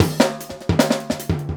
LOOP39--02-R.wav